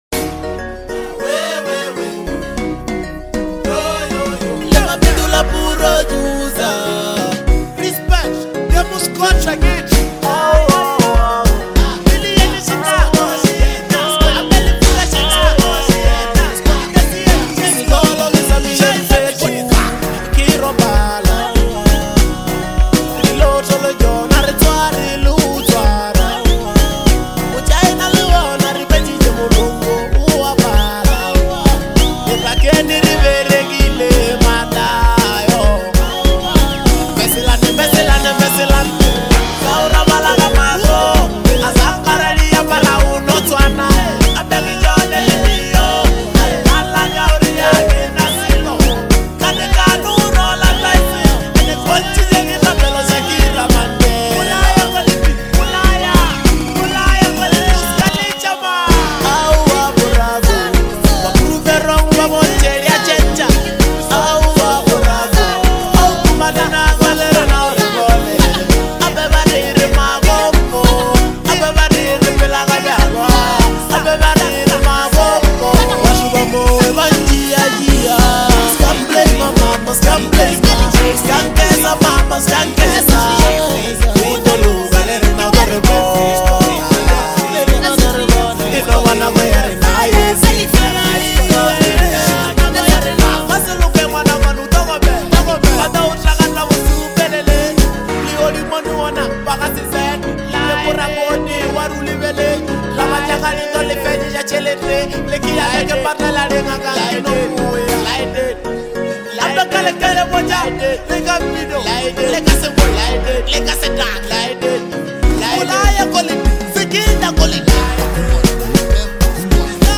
hard hitting Amapiano banger
razor sharp delivery and commanding flow